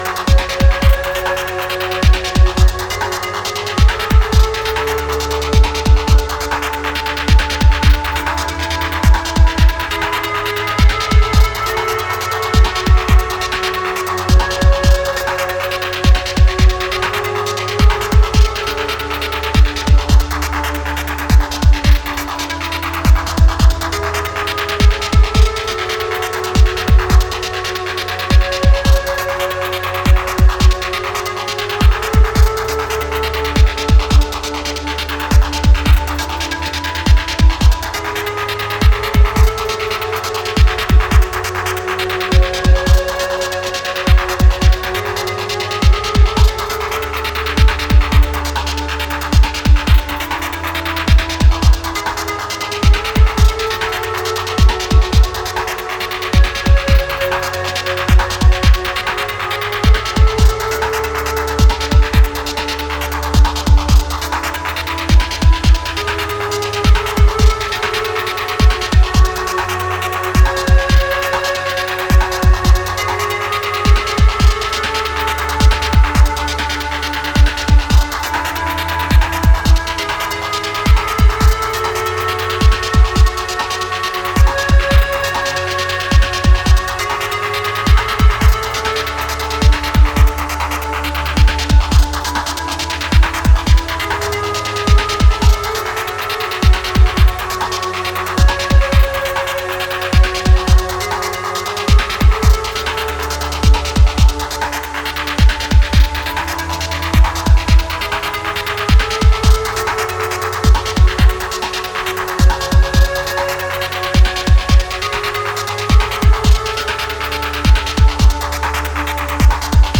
Early hour techno.